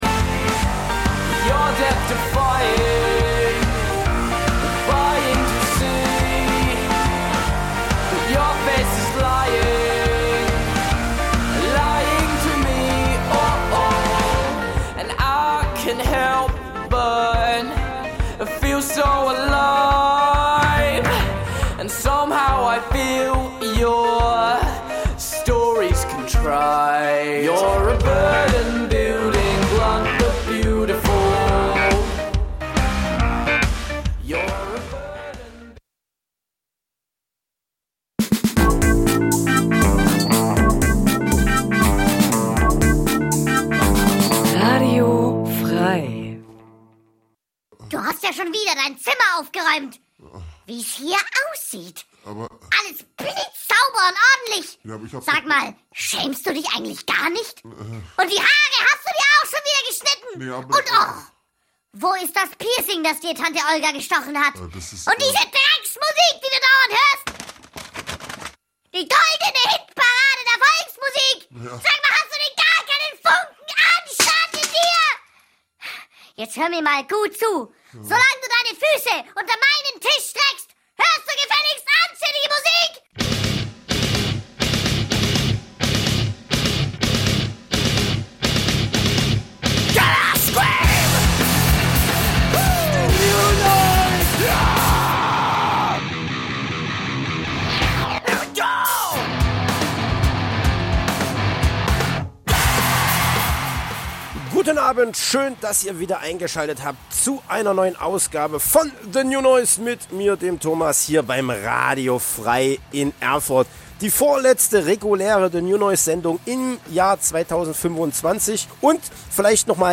...24 Jahre of total Krach... die beste Mischung aus genialen Neuvorstellungen und unerl�sslichen Konzerttips... aus dem old school, Metal-, Rock 'n' Roll-, Grind- und Hardcore- und sonstigen "gute Musik"- Bereichen...